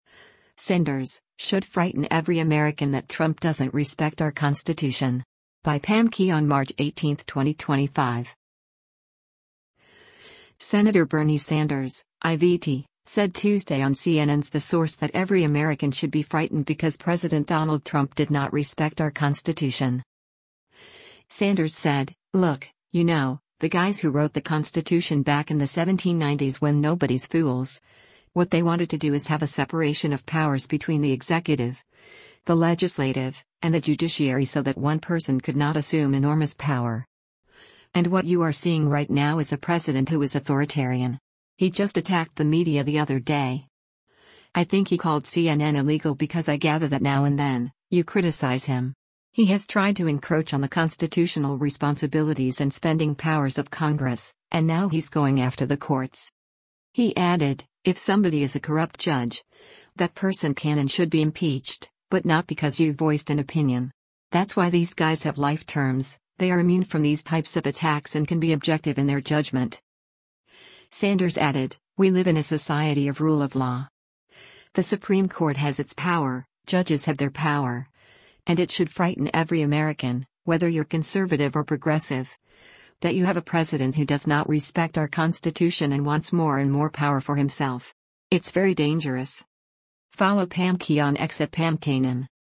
Senator Bernie Sanders (I-VT) said Tuesday on CNN’s “The Source” that every American should be frightened because President Donald Trump did not “respect our Constitution.”